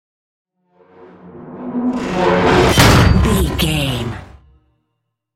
Electronic whoosh to metal hit
Sound Effects
Atonal
futuristic
intense
woosh to hit